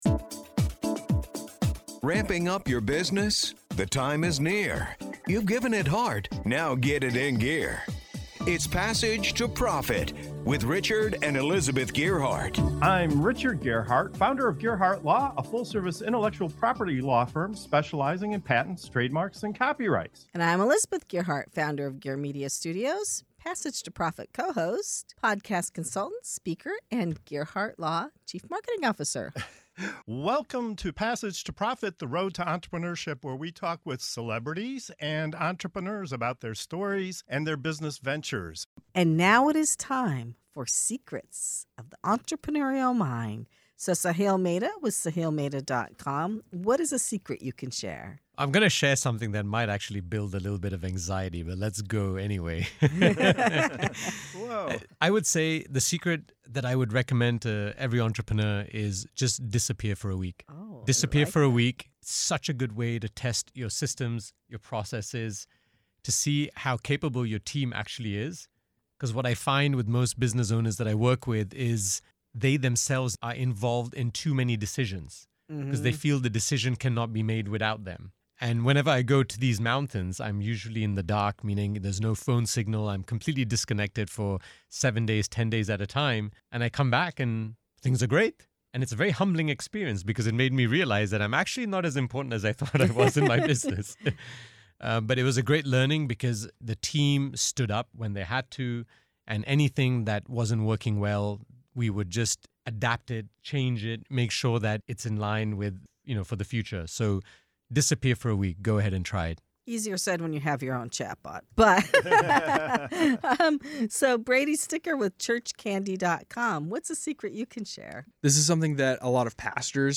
What if the fastest way to grow your business is to step away from it? In this episode of Secrets of the Entrepreneurial Mind, seasoned founders and experts share the counterintuitive habits that actually drive sustainable success.